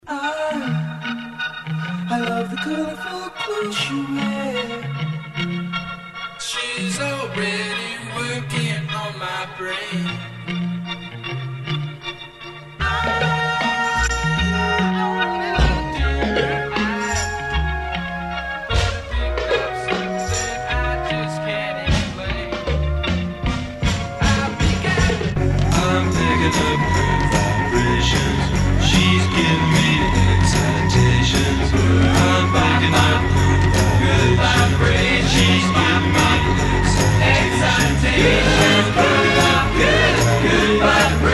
ALL TRACKS DIGITALLY REMASTERED